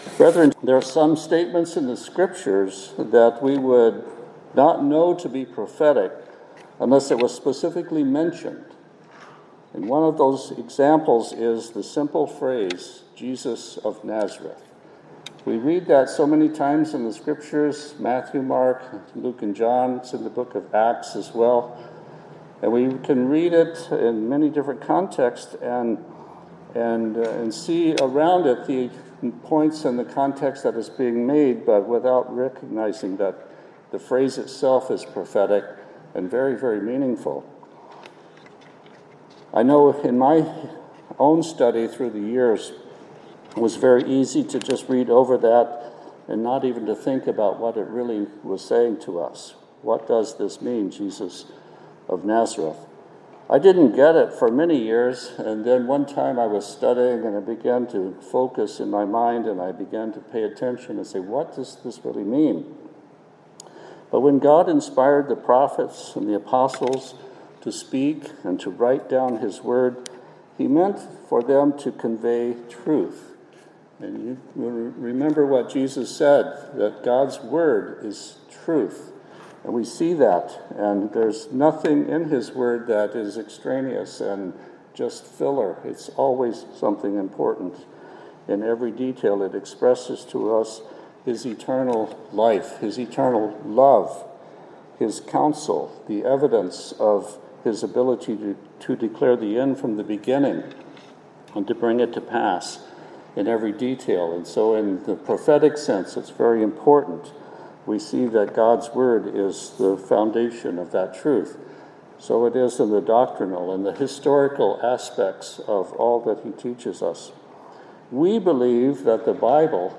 "Jesus of Nazareth" is how Christ was identified in many significant passages of Scripture. This sermon considers the meaning of the word "Nazareth," and how this connects with several prophecies about the Messiah who was to come, and who would be described and identified by the meaning of this word.